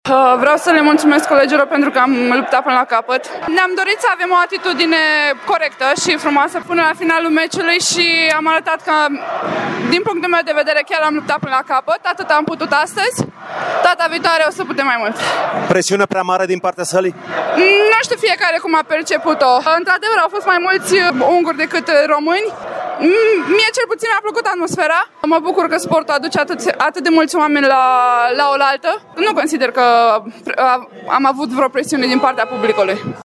Ea a vorbit despre întâlnirea de astăzi: